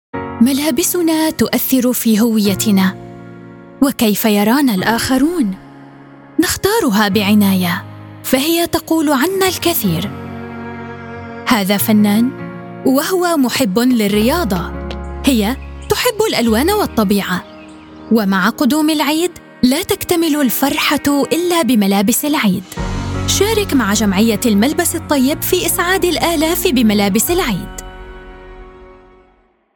Female
Adult (30-50)
Television Spots
Television Commercial
All our voice actors have professional broadcast quality recording studios.